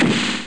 SE_HIT.mp3